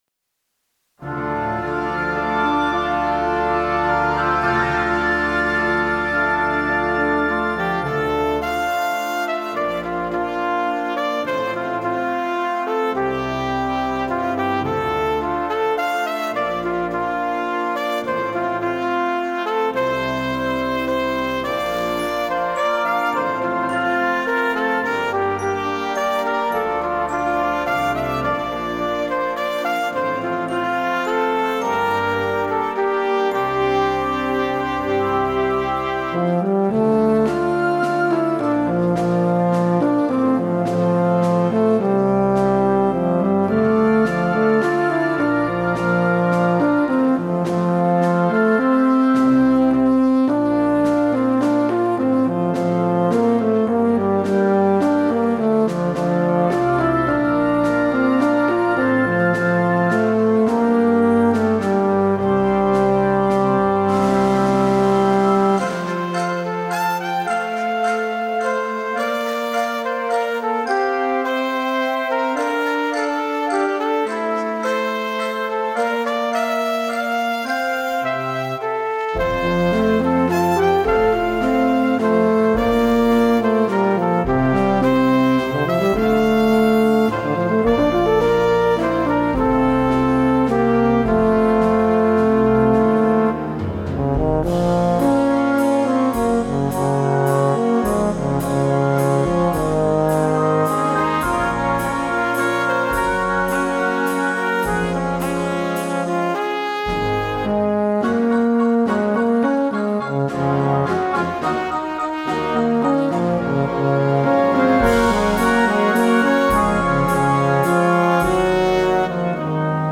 Solo Bb Trumpet
Solo Euphonium
Timpani
Glockenspiel